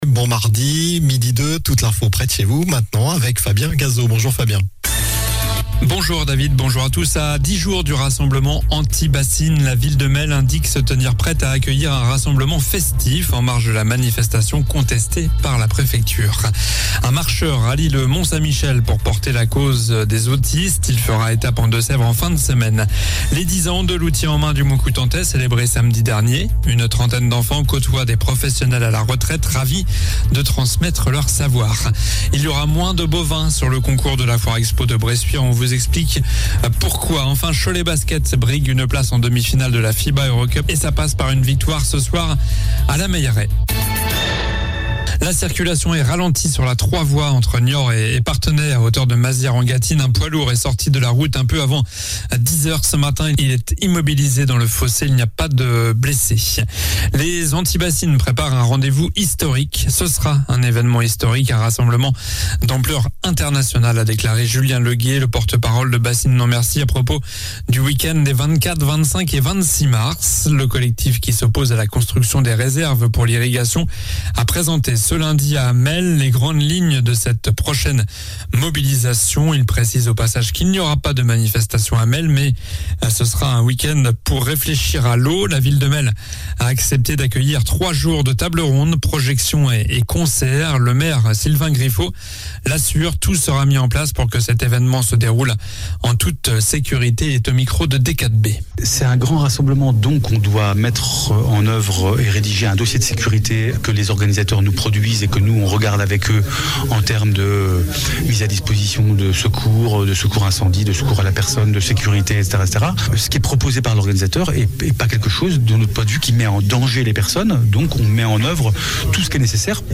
Journal du mardi 14 mars (midi)